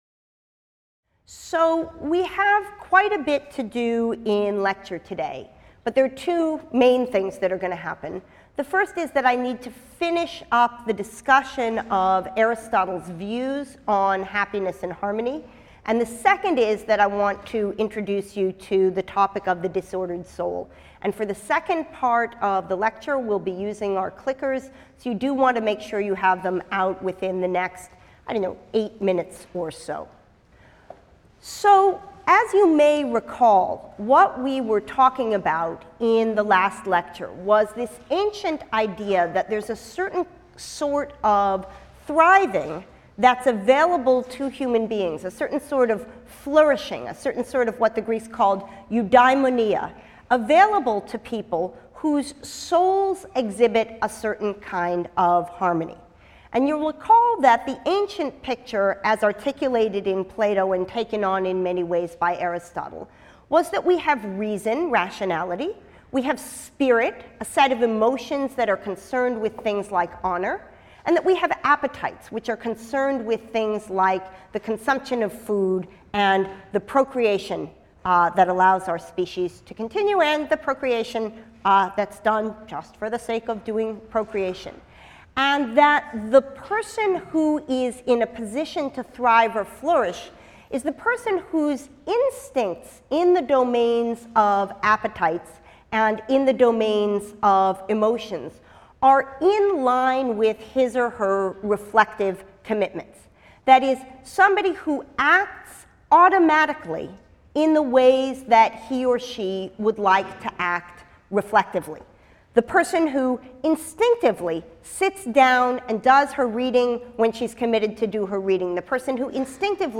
PHIL 181 - Lecture 6 - The Disordered Soul: Thémis and PTSD | Open Yale Courses